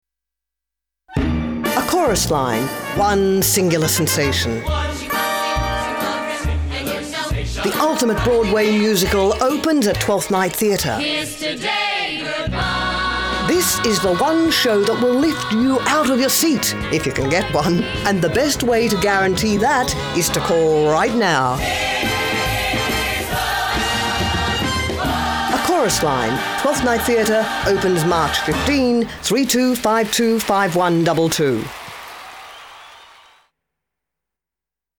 Radio Advertisement - A Chorus Line
ACL_30_SEC_RADIO_AD.mp3